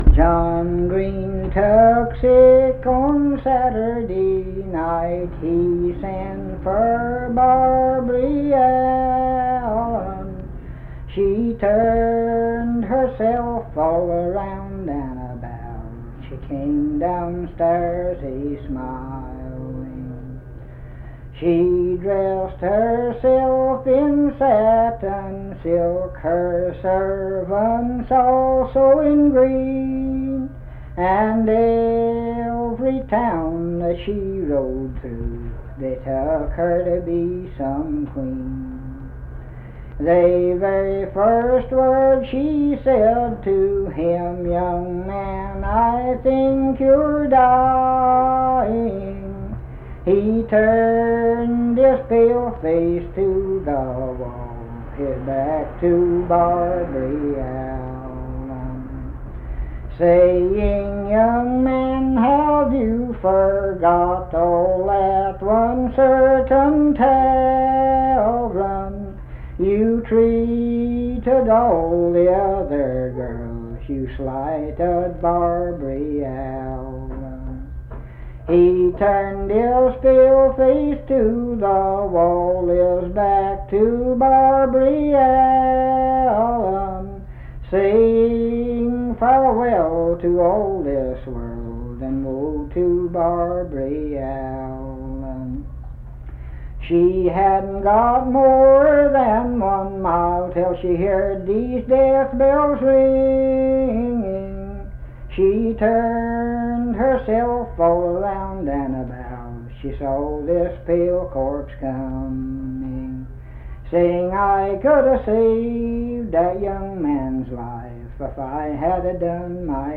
Unaccompanied vocal music
Performed in Naoma, Raleigh County, WV.
Voice (sung)